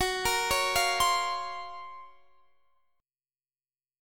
Listen to GbM#11 strummed